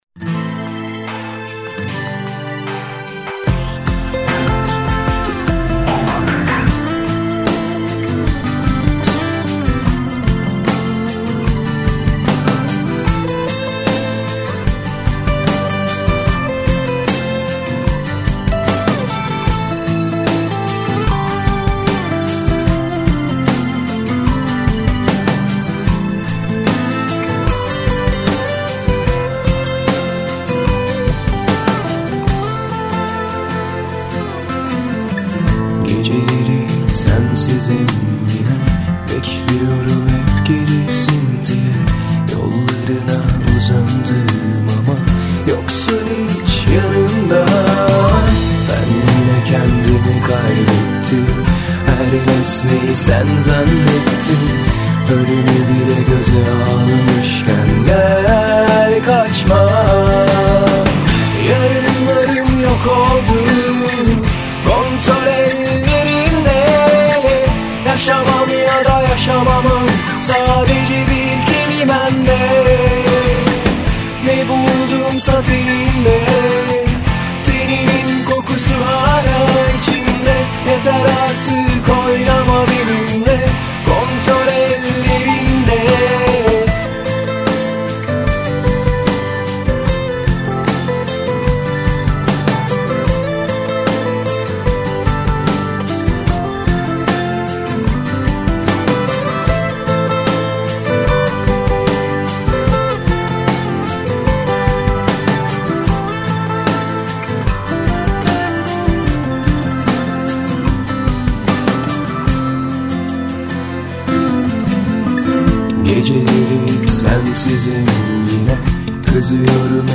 Modern Turkish Music
Alternative rock